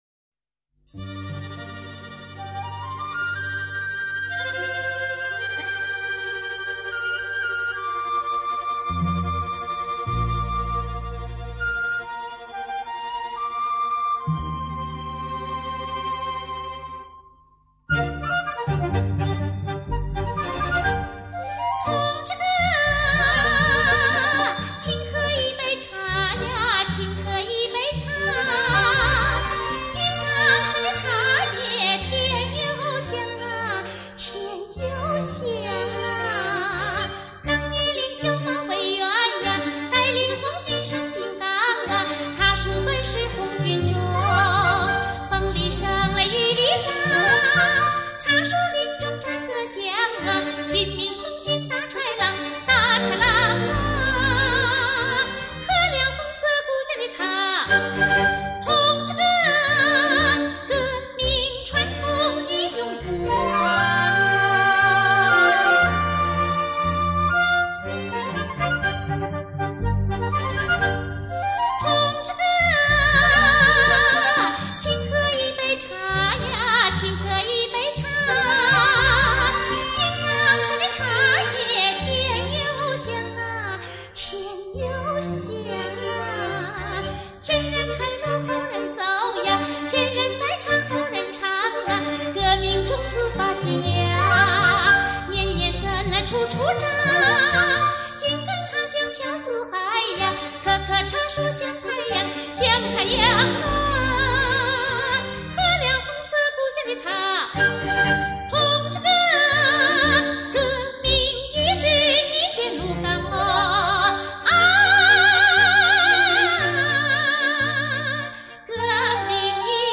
独一无二的魅力唱腔，蕴味浓郁的地方民族风情。